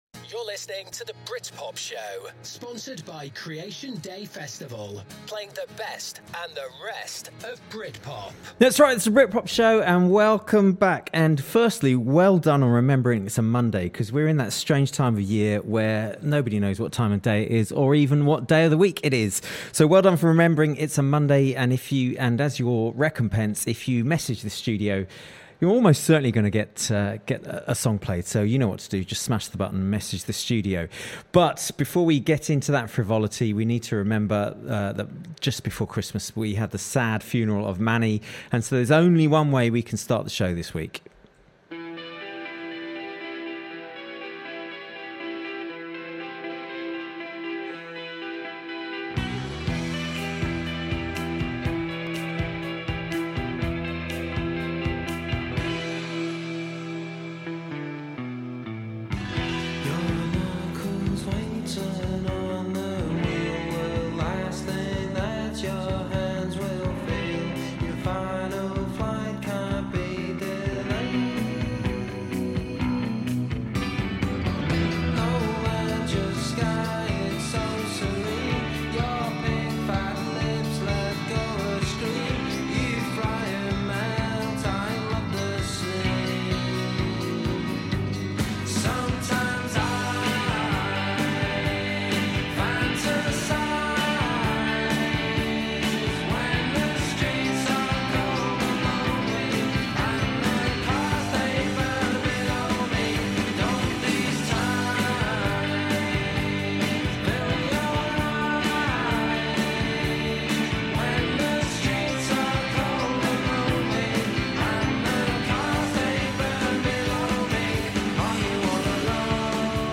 A mix of classic Britpop tracks, Britpop songs you thought you’d forgotten, and some you ought to know but don’t yet - with the odd interview with Britpop royalty thrown in!